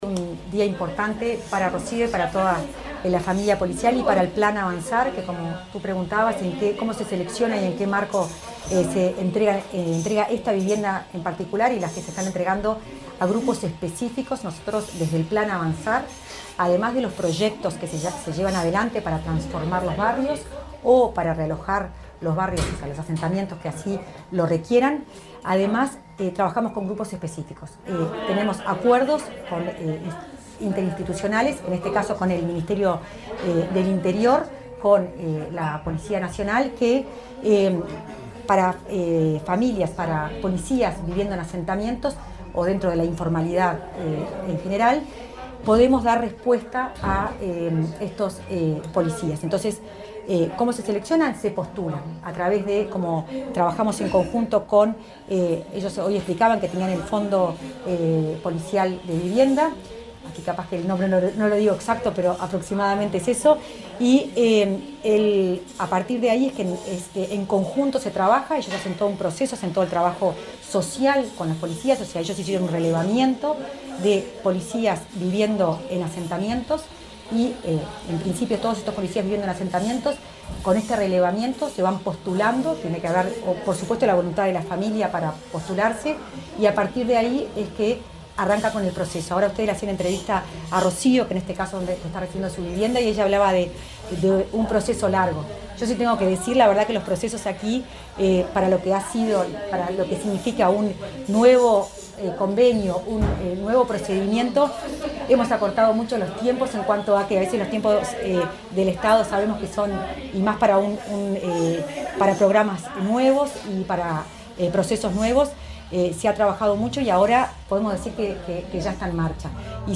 Declaraciones de la directora de Integración Social y Urbana del MVOT, Florencia Arbeleche
La directora de Integración Social y Urbana, del Ministerio de Vivienda y Ordenamiento Territorial (MVOT), Florencia Arbeleche, dialogó con la prensa,